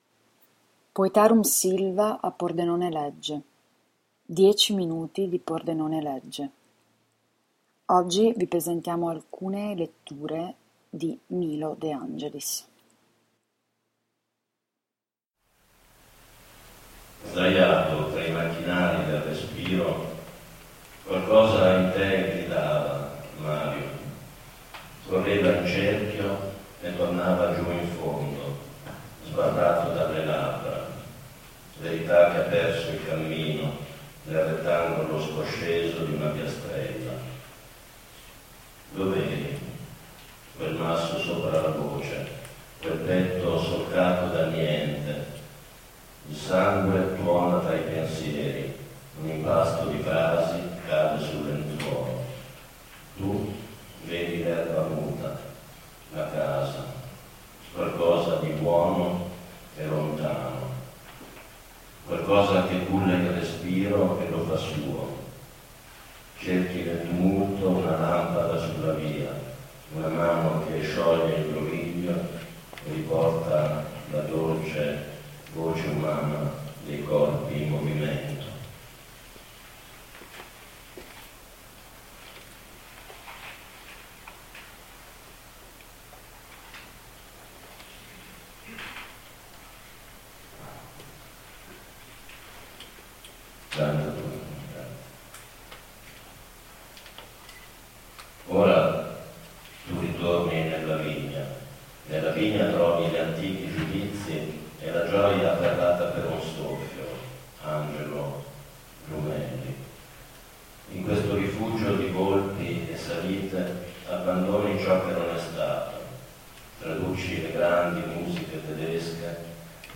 Incontri e agguati è il titolo dell’ultima raccolta Mondadori e dell’evento che si è tenuto presso Palazzo Gregoris ieri mattina ed è anche il titolo del dialogo appassionato tra Emanuele Trevi e lo stesso poeta.
Proverò a riproporne una parte qui, una selezione di tre momenti, ma intendo lasciare spazio prima all’ascolto, alla lettura ad alta voce, che inizia con un testo dedicato a Mario Benedetti.